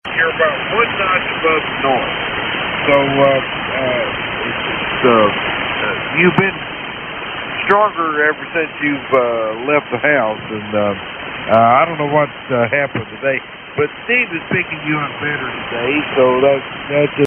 Reuter RDR55E Test Sample Off Air Recordings
(as recorded via it's front panel headphone jack - Sangean DAR-101)
- 80 Meter Amateur LSB with DNR on